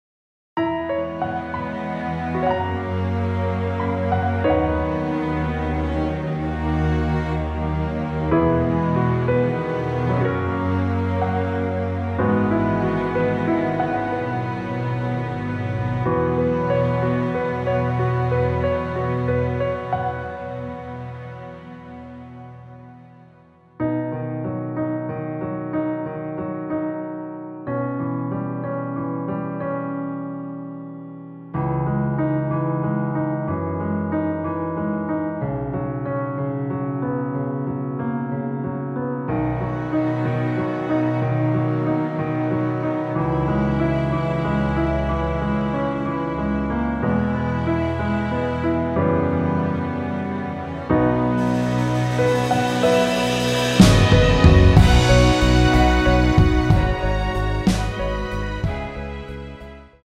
원곡보다 짧은 MR입니다.(아래 재생시간 확인)
원키에서(-2)내린 (짧은편곡)MR입니다.
앞부분30초, 뒷부분30초씩 편집해서 올려 드리고 있습니다.